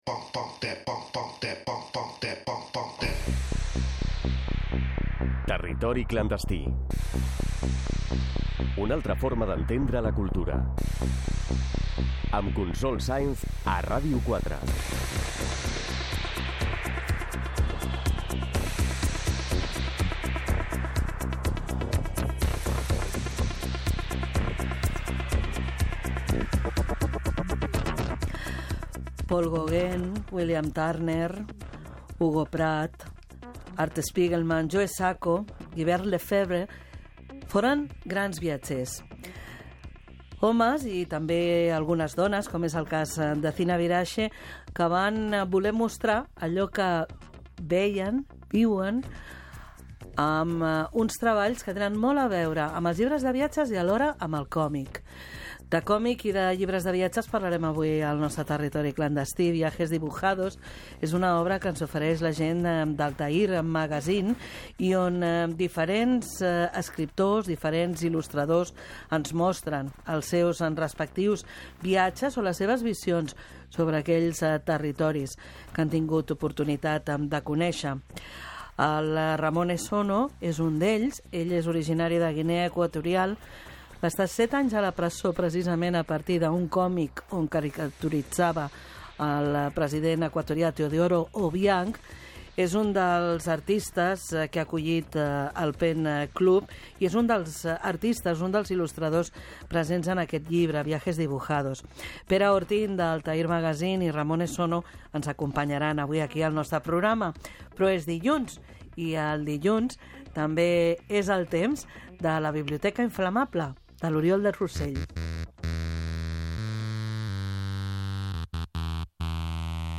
Careta del programa, sumari i secció "La biblioteca infamable"